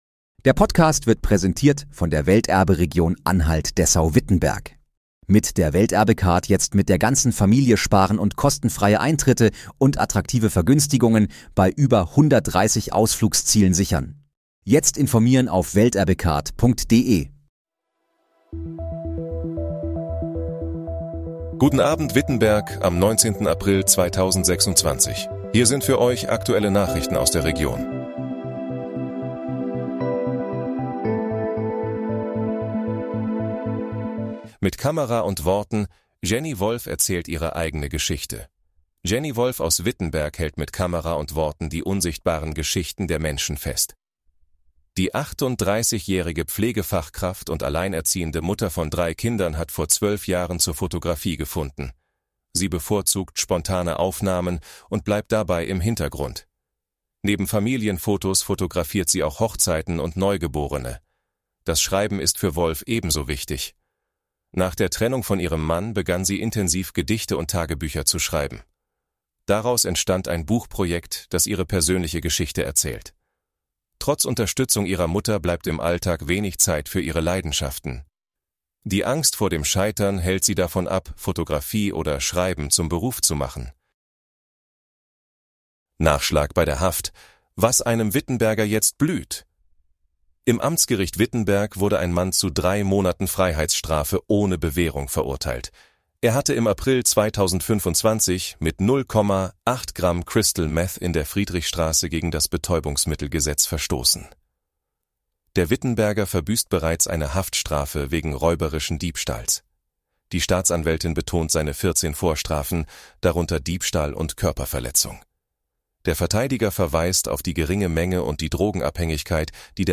Hier sind die aktuellen Nachrichten für die Region Wittenberg vom